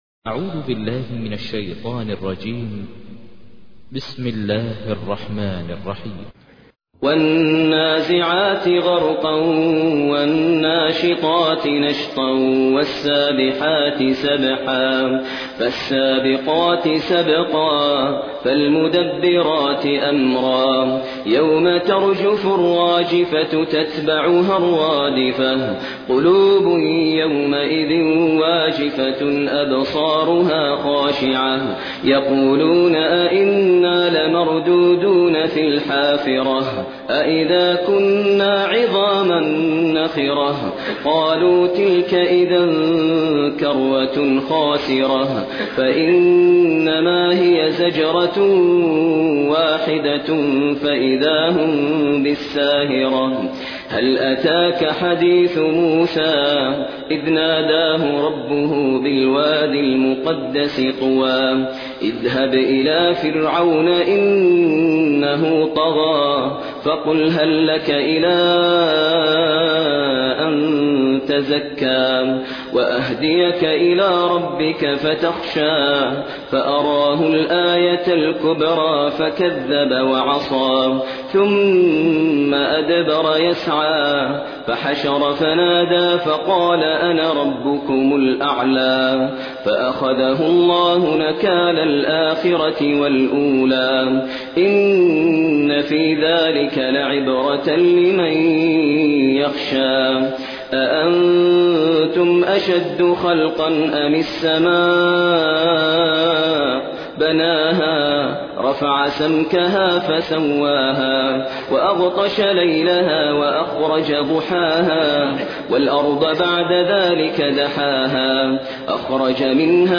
تحميل : 79. سورة النازعات / القارئ ماهر المعيقلي / القرآن الكريم / موقع يا حسين